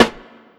Mozart Theme Snare.wav